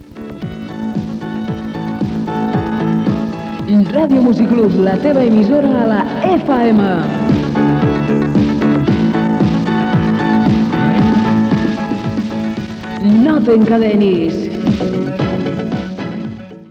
587d796abfba5b44589ffde4347c7218fa98e5a8.mp3 Títol Music Club Emissora Music Club Titularitat Tercer sector Tercer sector Lliure Descripció Indicatiu de l'emissora Data emissió 198?